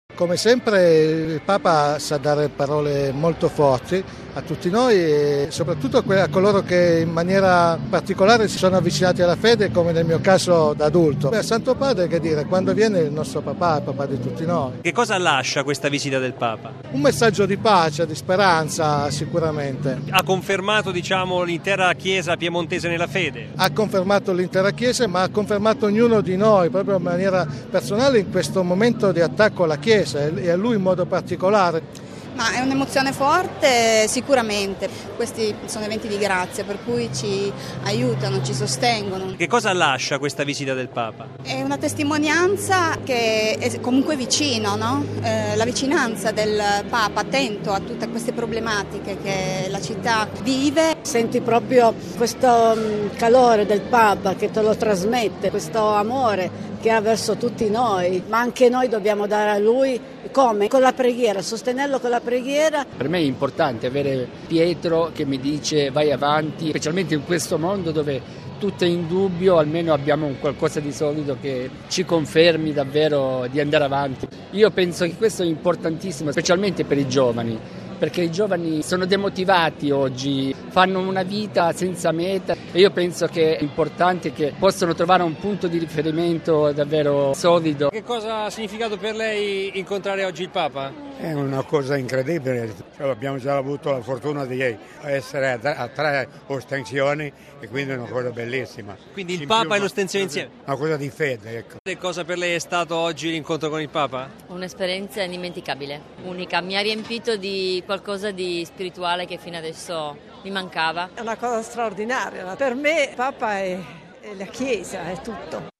E per concludere ascoltiamo, sulla visita del Papa a Torino, i commenti di alcuni fedeli